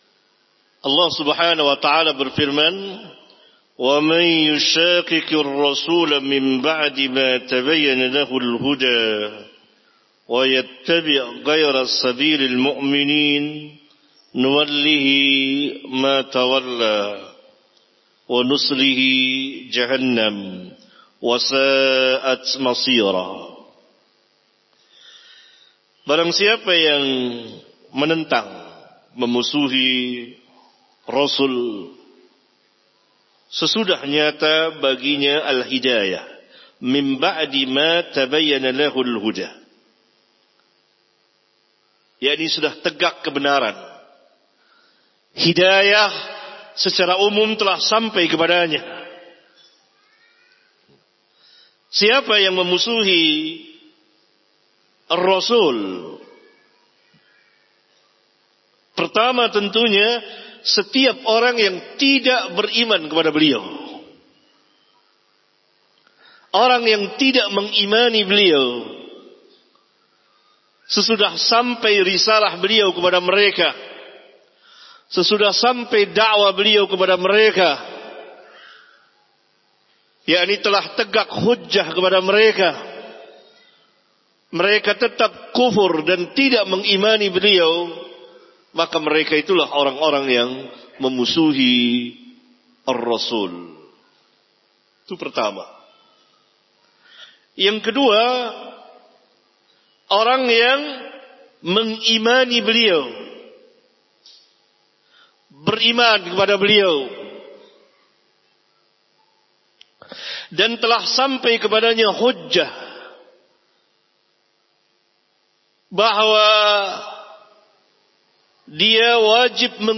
AUDIO KAJIAN